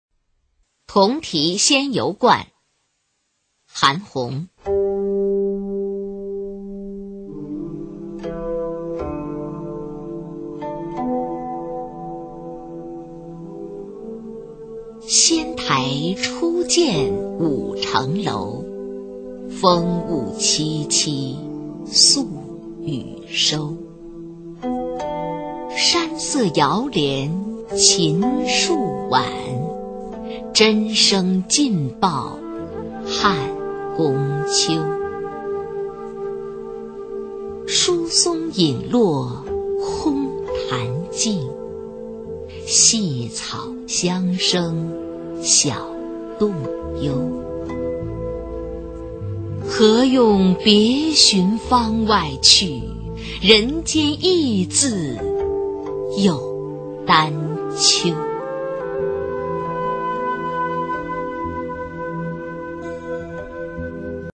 [隋唐诗词诵读]韩翃-同题仙游观 古诗文诵读